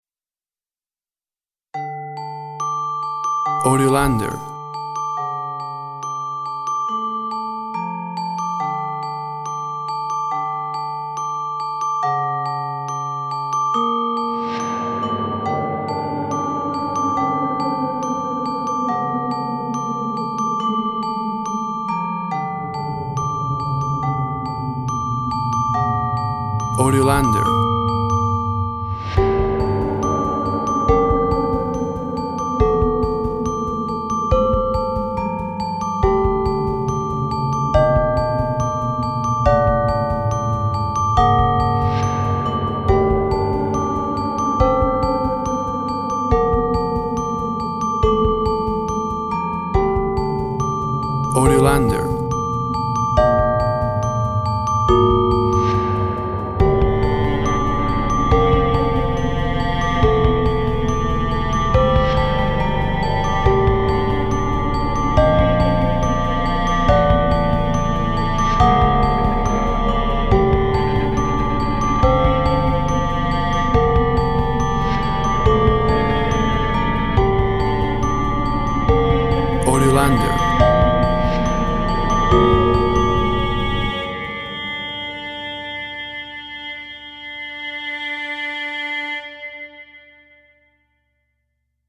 Tempo (BPM) 70